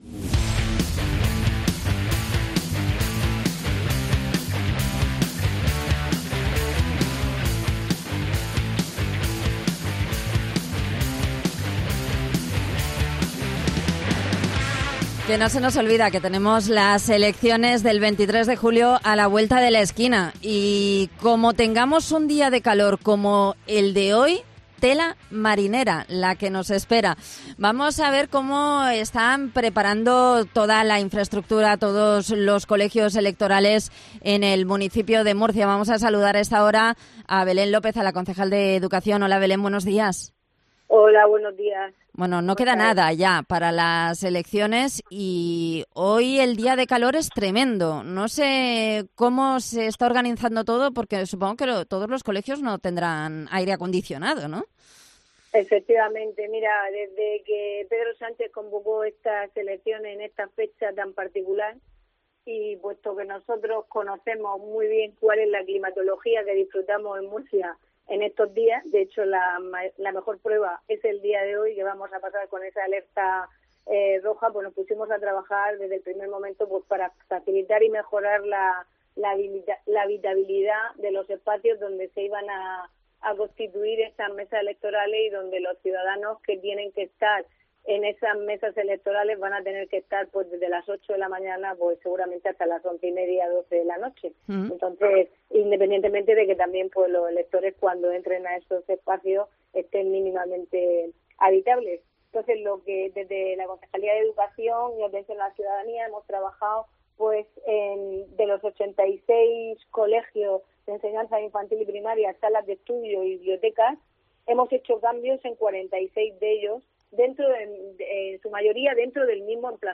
Belén López, concejala de Educación de Murcia